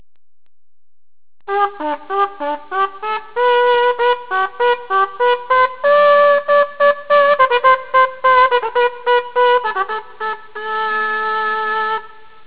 The cornett is a wooden instrument with a distinctive slightly curved shape.
Cornett Sound Clips
It was considered in the 17th century to be the only instrument to have the same expressive qualities as the human voice, and Bach used it in some of his cantatas.